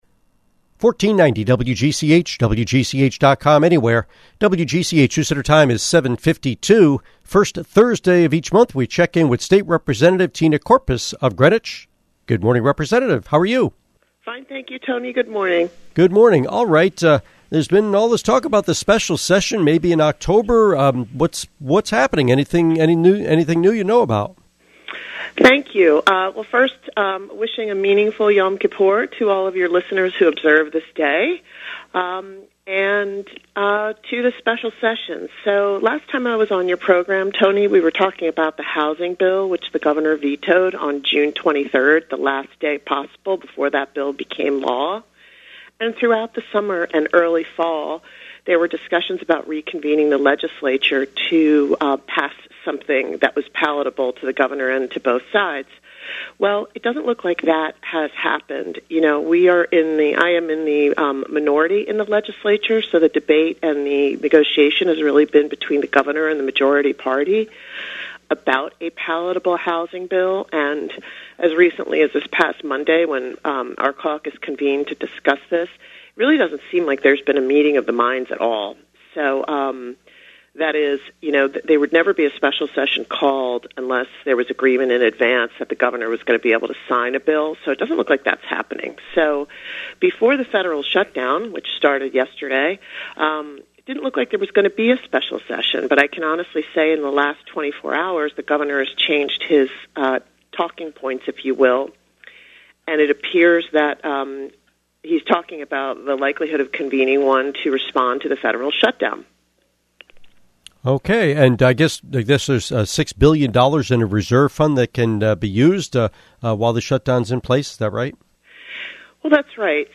Interview with State Representative Tina Courpas